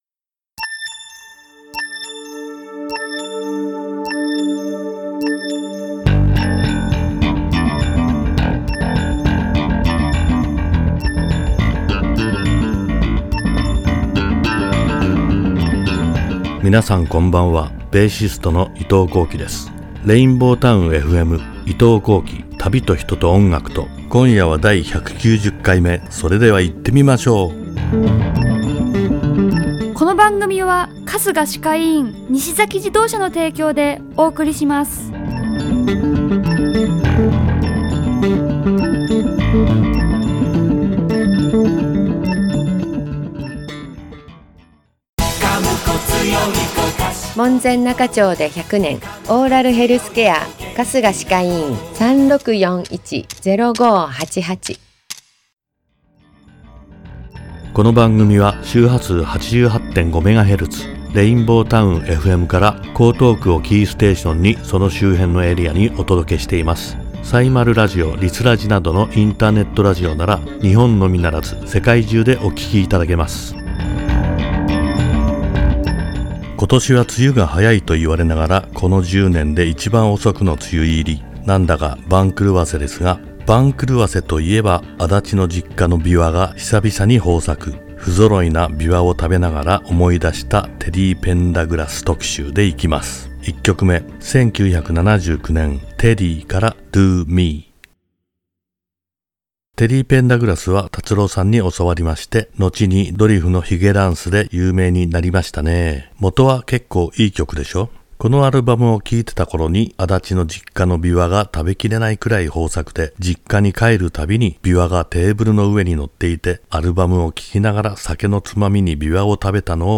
※アーカイブ・オンデマンドでは、トーク内容のみで楽曲はかけておりません。ご了承ください。